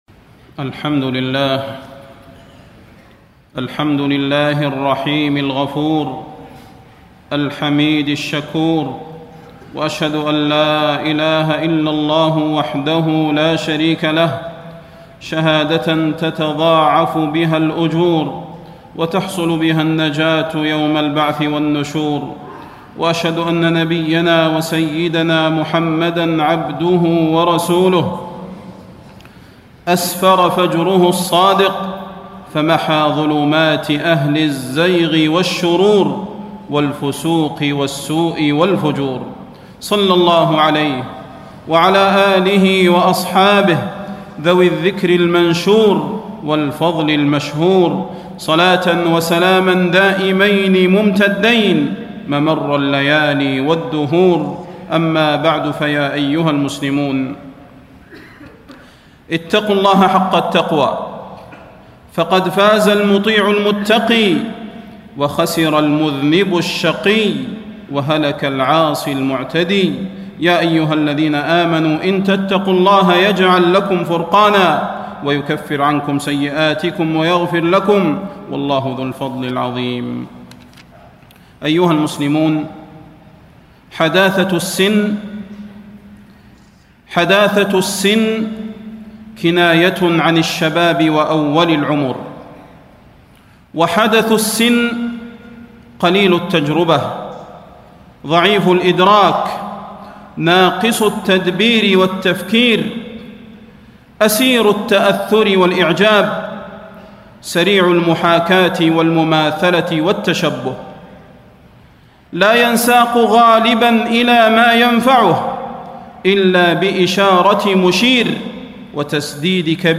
تاريخ النشر ٤ ربيع الأول ١٤٣٣ هـ المكان: المسجد النبوي الشيخ: فضيلة الشيخ د. صلاح بن محمد البدير فضيلة الشيخ د. صلاح بن محمد البدير التحذير من ضياع الشباب